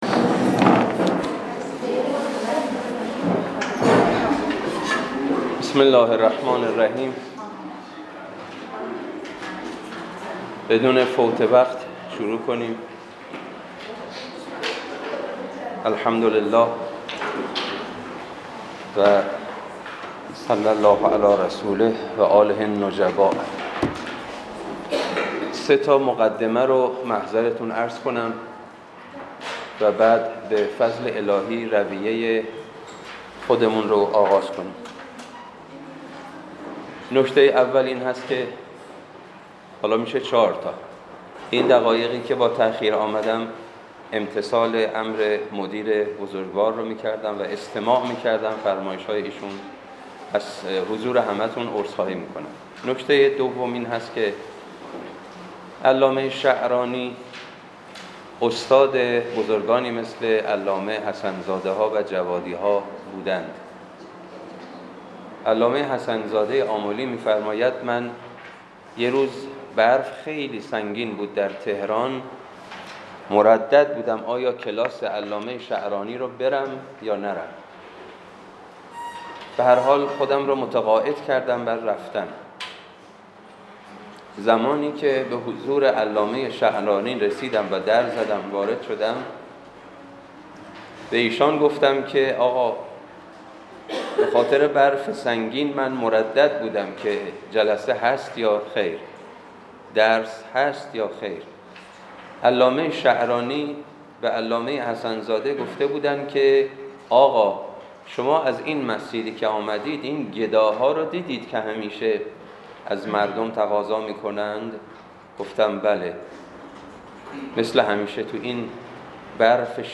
درس اخلاق